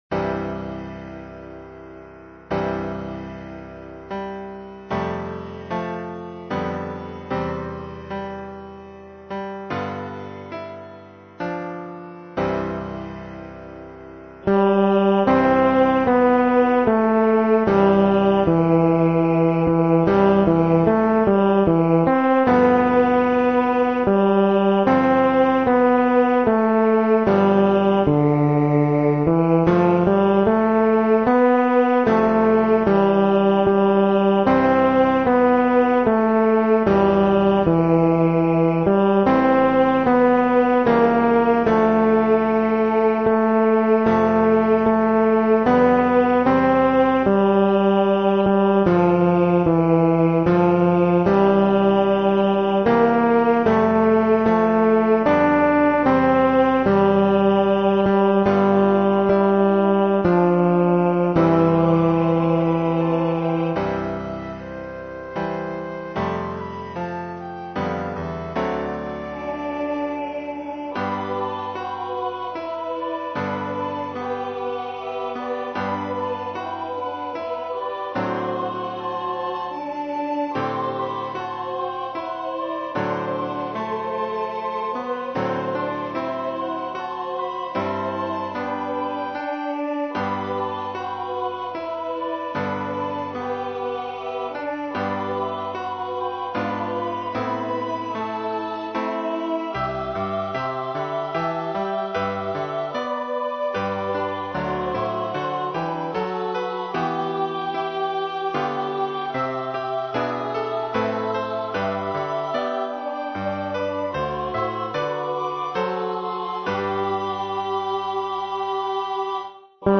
hymn J63 arranged Farnworth